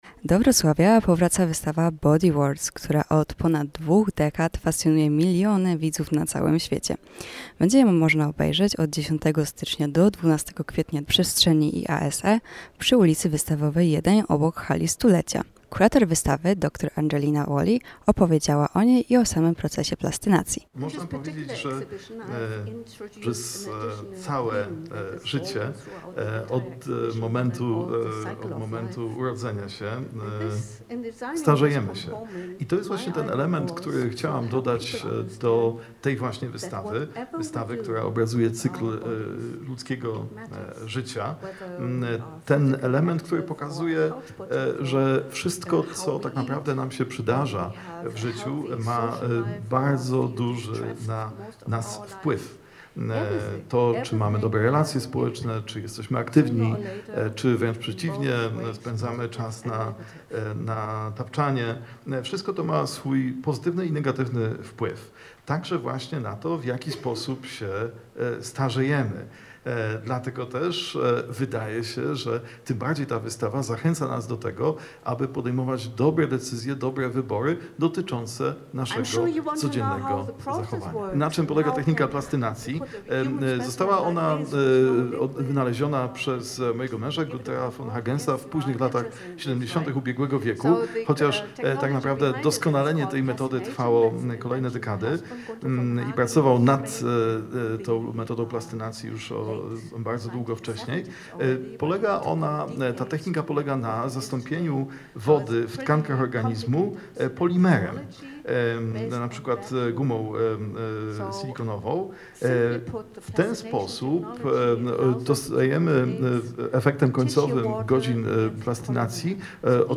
Wystawa Body Worlds jest dostępna dla zwiedzających między 10 stycznia a 12 kwietnia 2026 w Hali IASE (przy Hali Stulecia).
0901-wystawa-body-worlds-relacja.mp3